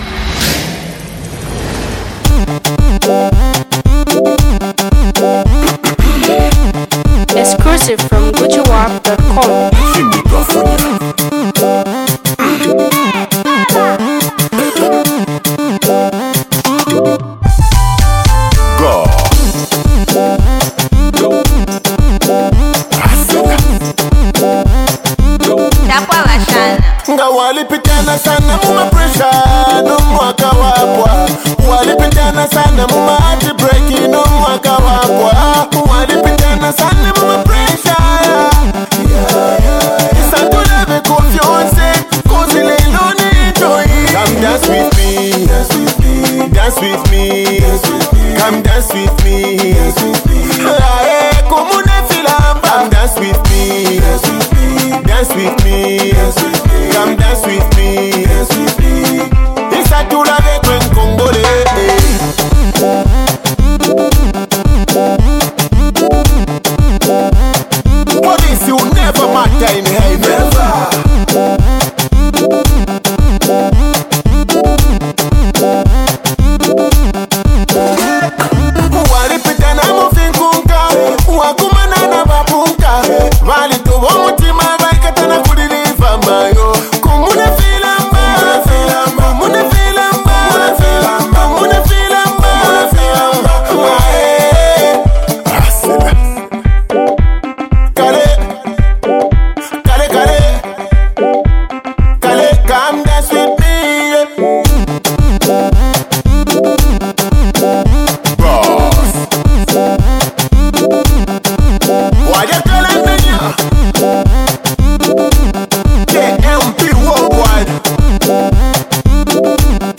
afrobeat/dancehall singer
for it’s dancehall stunning tune.
Songwriter, Dancer and Singer